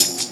Track 02 - Percussion OS 03.wav